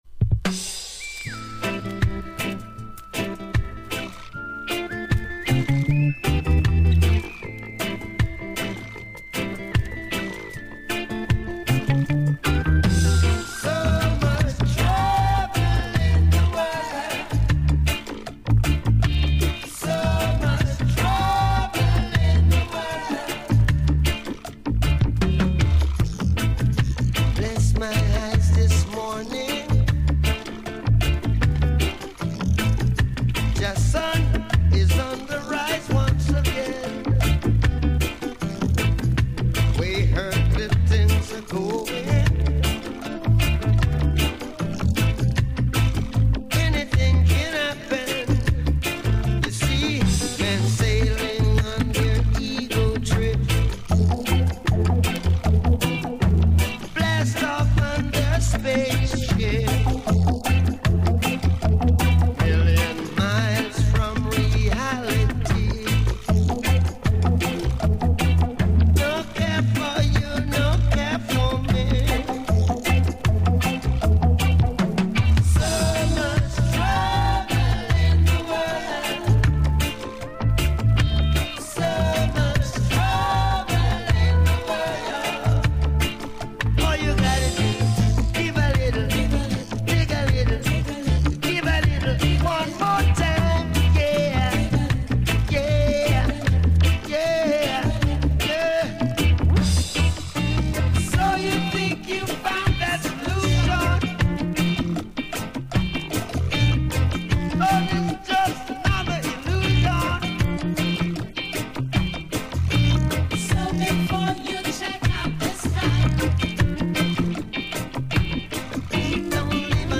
Roots & Dub